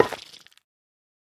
Minecraft Version Minecraft Version 25w18a Latest Release | Latest Snapshot 25w18a / assets / minecraft / sounds / block / sculk_catalyst / place4.ogg Compare With Compare With Latest Release | Latest Snapshot